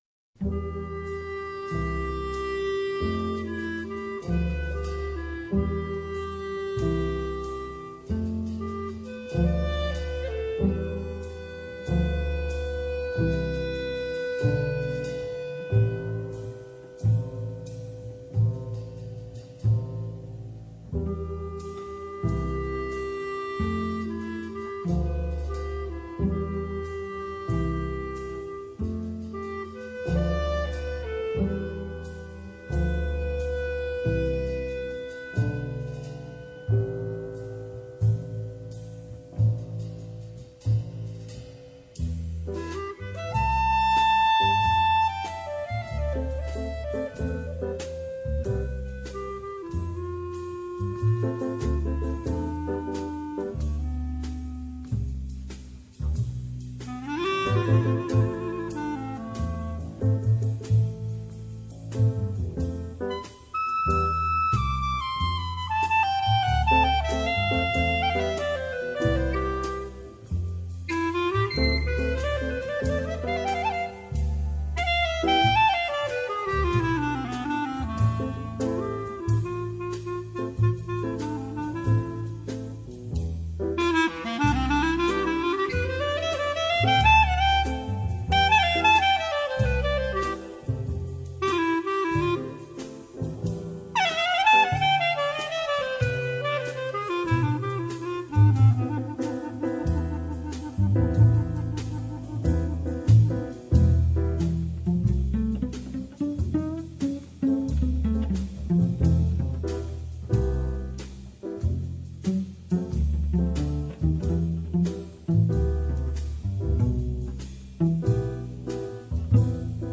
stunningly beautiful sleepy jazz classic